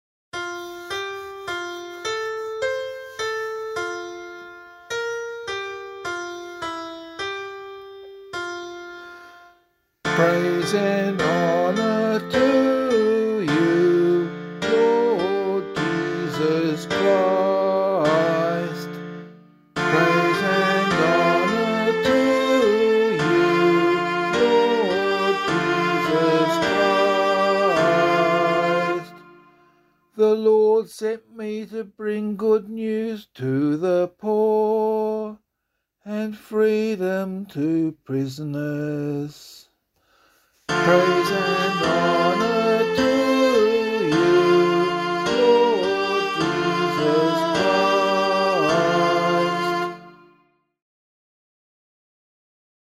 Gospelcclamation for Australian Catholic liturgy.